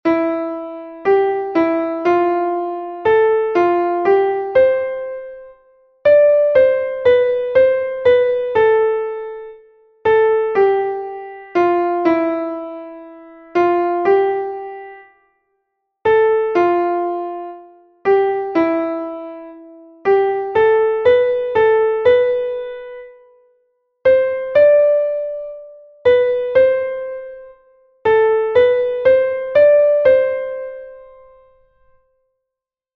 Here, there are two exercises in a 4/4 time signature and one exercise in a 2/4 time signature.